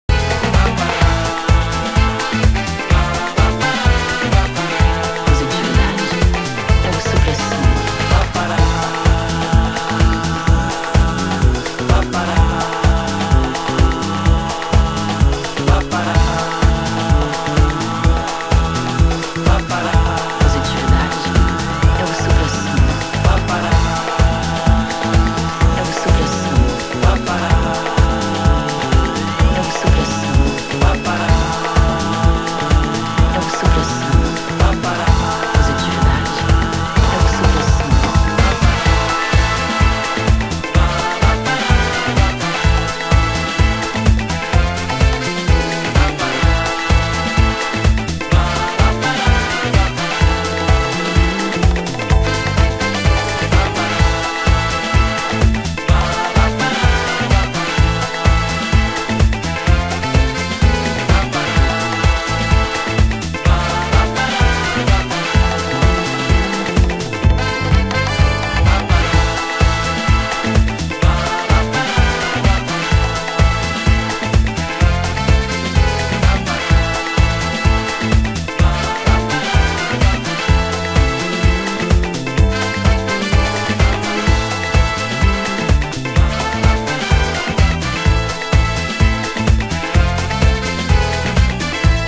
盤質：軽いスレ、小傷、少しチリパチノイズ有　　　ジャケ：スレ有